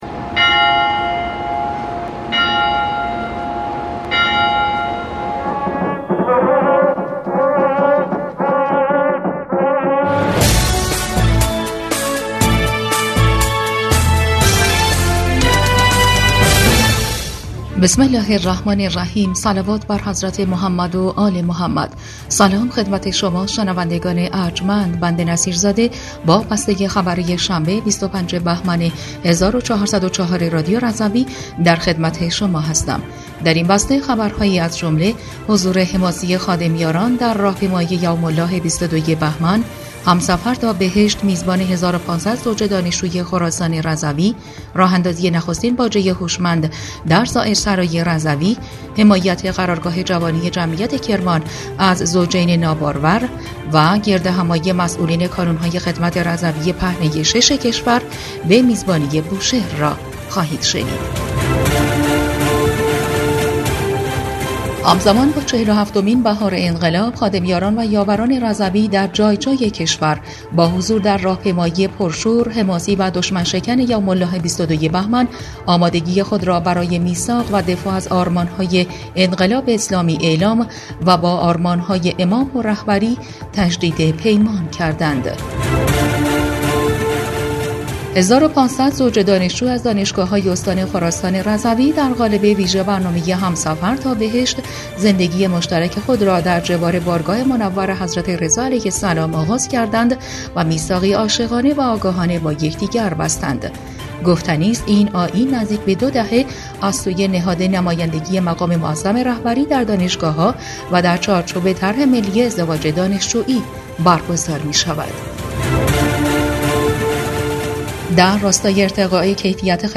بسته خبری ۲۵ بهمن ۱۴۰۴ رادیو رضوی؛